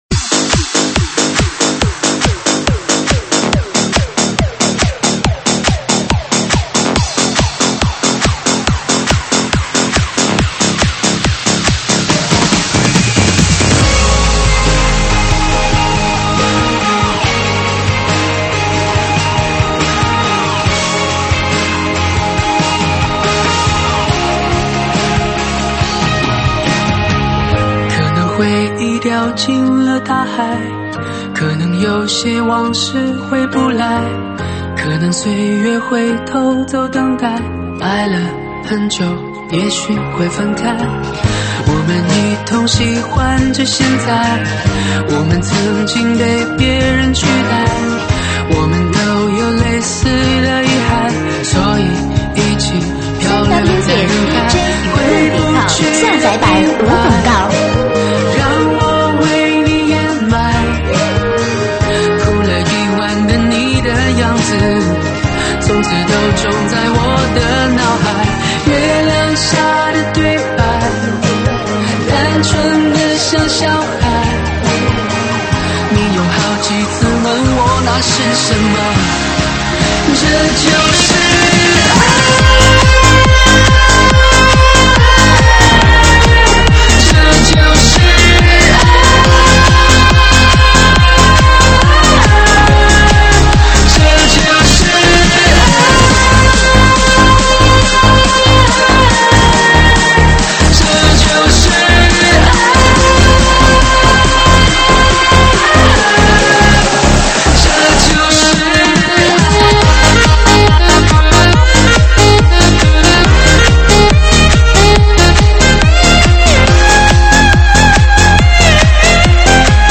Mix版 手机播放
舞曲类别：独家发布